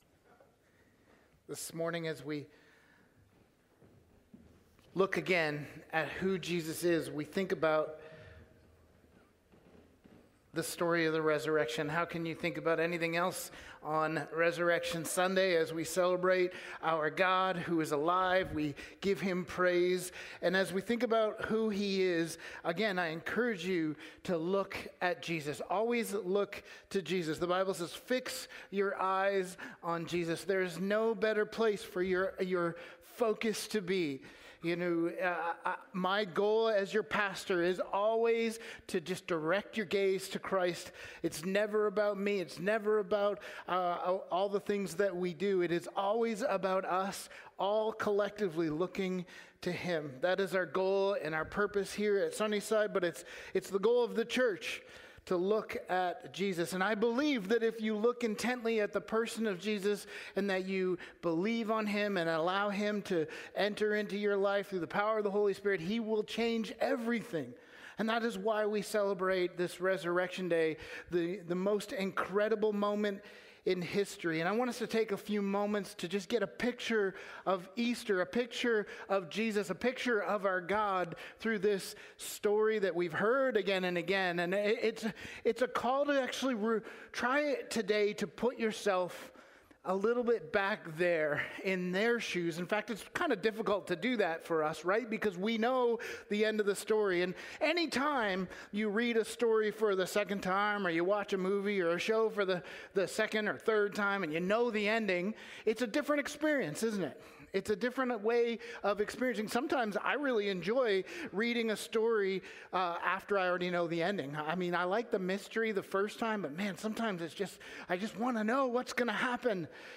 Download Download Portraits Of Christ Current Sermon Portraits of Christ: ALIVE! Easter Sunday